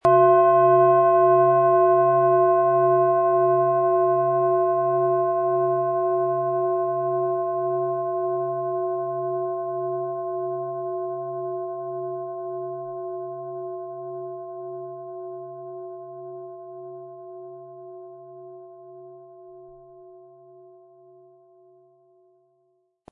Biorhythmus Körper Klangschale, Ø 17 cm im Sound-Spirit Shop | Seit 1993
Sanftes Anspielen mit dem gratis Klöppel zaubert aus Ihrer Schale berührende Klänge.
MaterialBronze